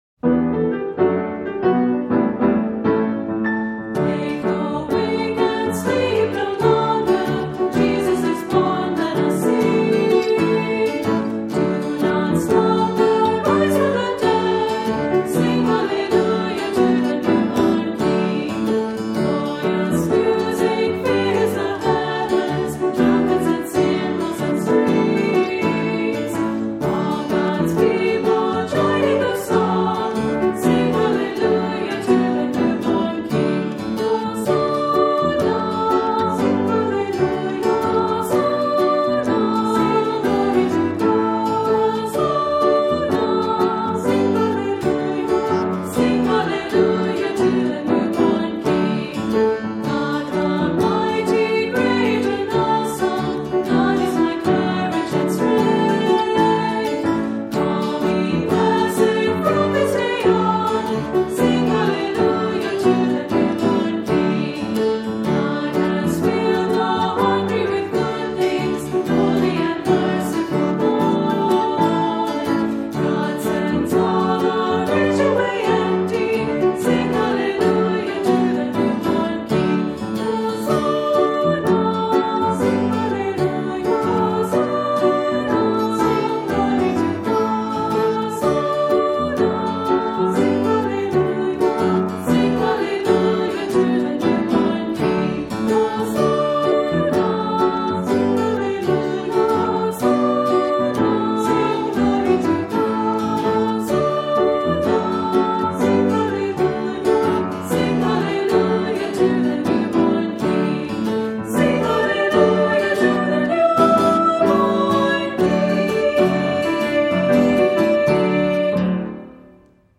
Voicing: Two-part Children's Choir - SA